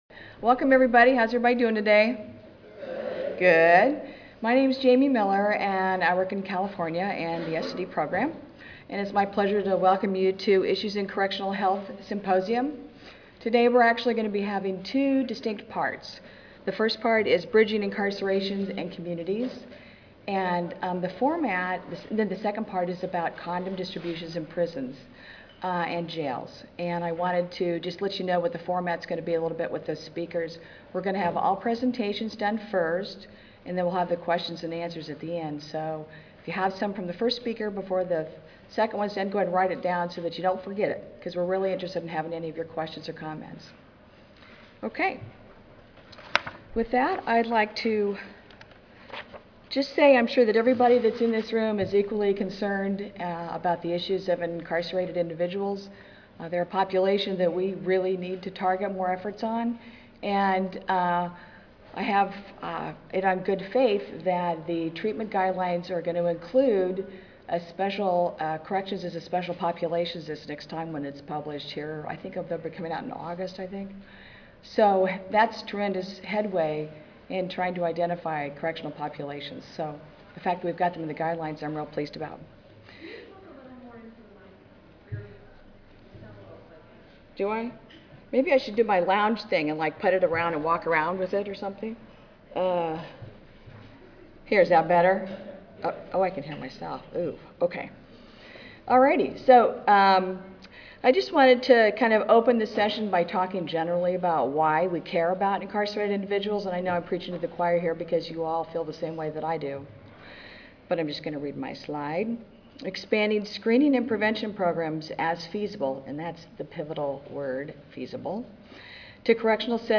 Grand Ballroom A (M4) (Omni Hotel)
Audio File Recorded presentation